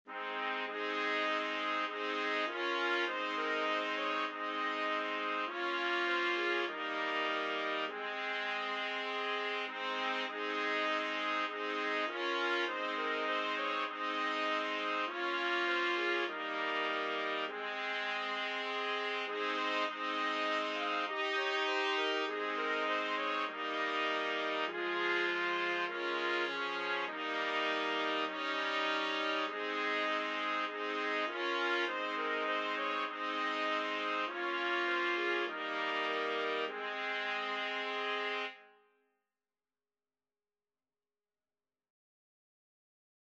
Trumpet 1Trumpet 2Trumpet 3Trumpet 4
4/4 (View more 4/4 Music)